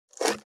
467,台所,野菜切る,咀嚼音,ナイフ,調理音,まな板の上,料理,
効果音厨房/台所/レストラン/kitchen食器食材